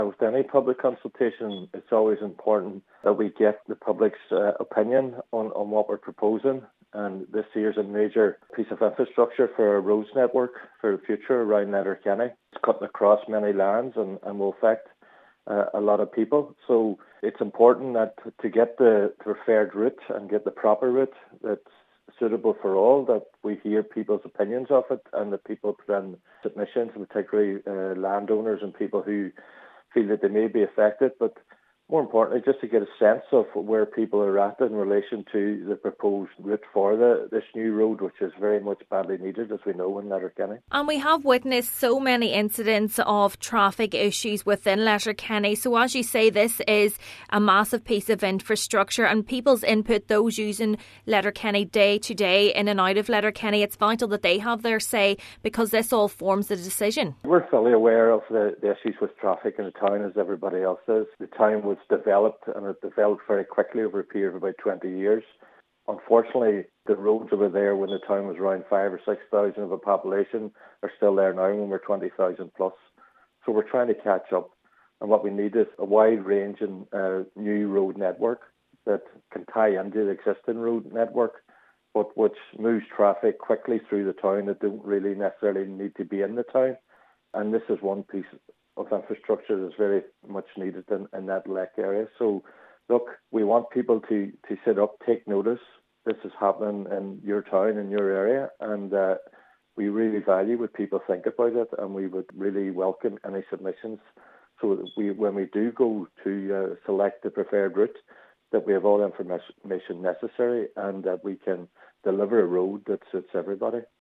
Cathaoirleach of the Letterkenny Milford Municipal District, Councillor Gerry McMonagle says the development is an important piece of infrastructure for the town: